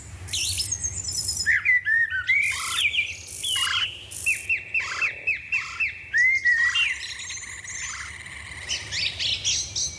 Bruitage – En forêt – Le Studio JeeeP Prod
Bruitage haute qualité créé au Studio.
En-foret.mp3